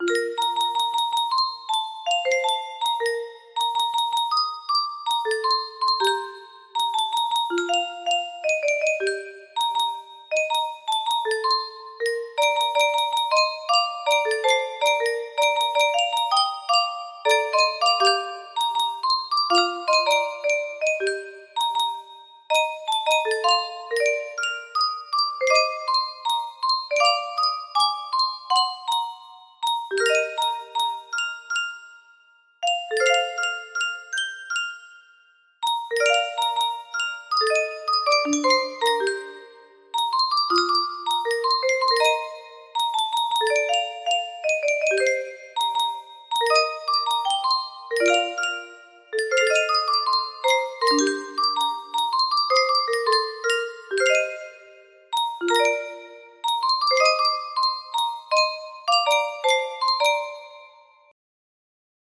Grand Illusions 30 (F scale)
code: A# major